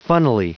Prononciation du mot funnily en anglais (fichier audio)
funnily.wav